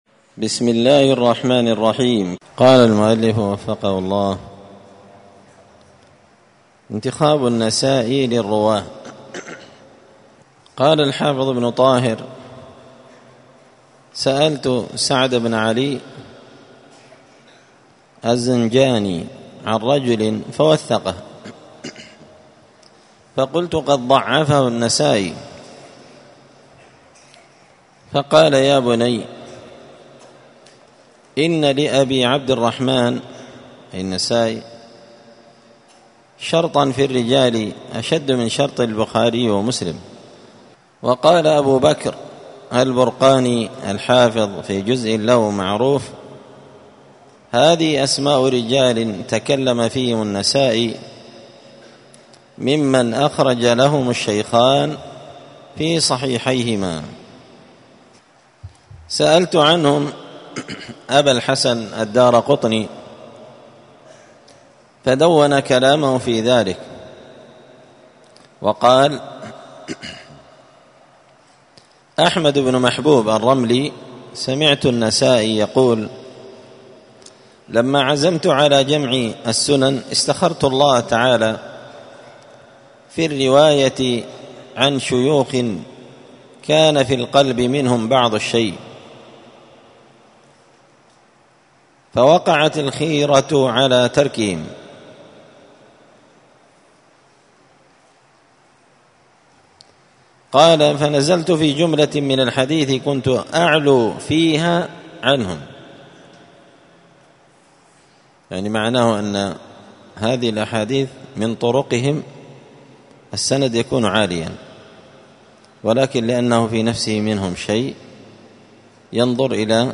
مسجد الفرقان قشن_المهرة_اليمن
*الدرس الثاني عشر (12) انتخاب النسائي للرواة*